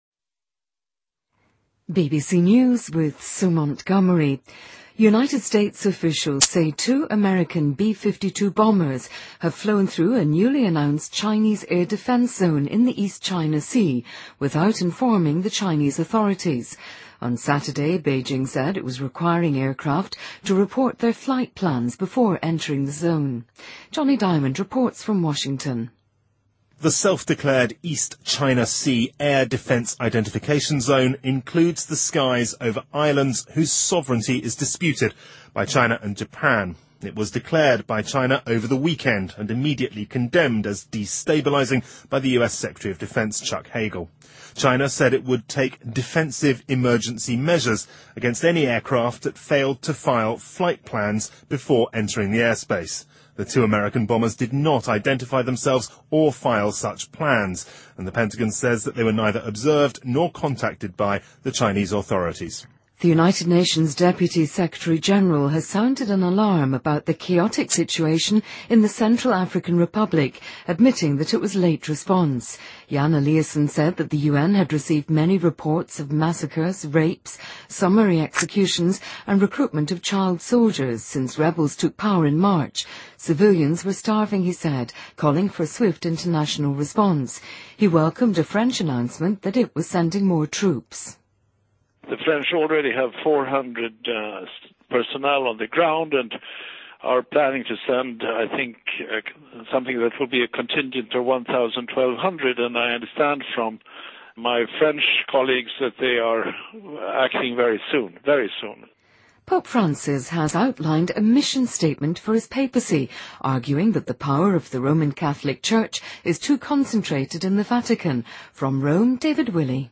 BBC news,美国B-52轰炸机飞越中国防空识别区